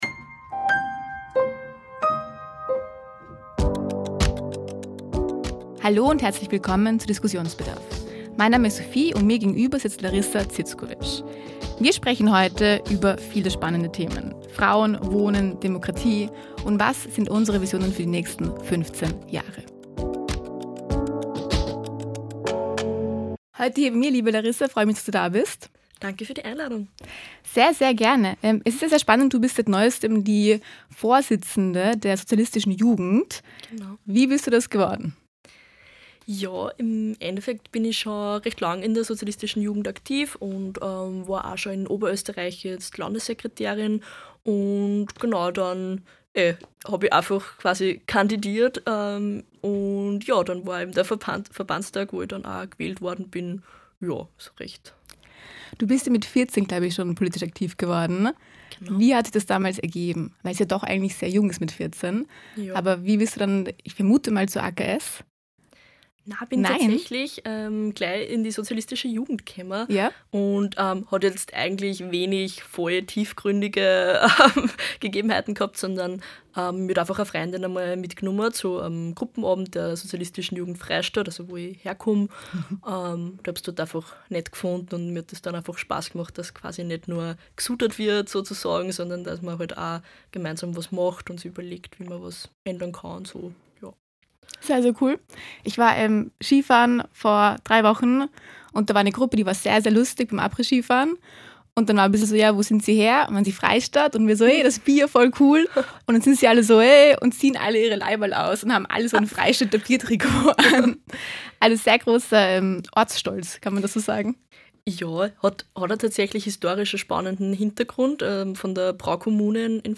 Produktion: T3 Podcaststudio Wien